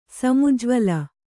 ♪ samujvala